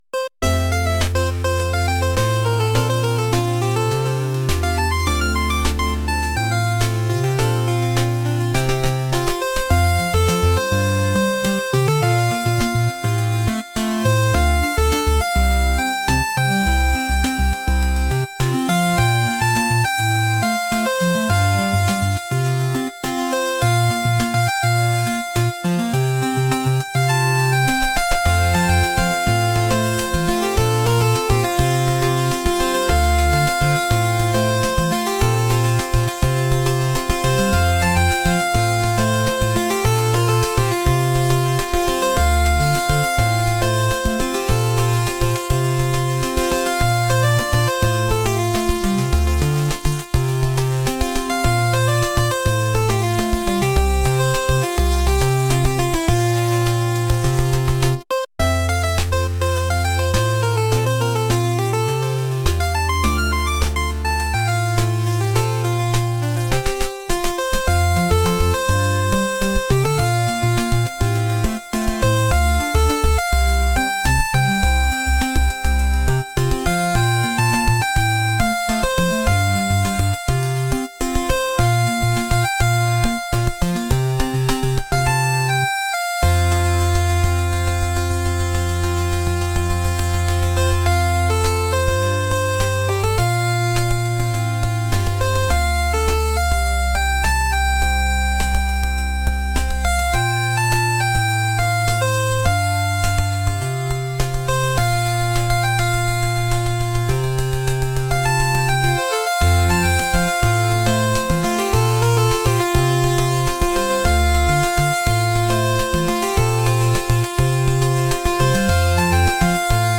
upbeat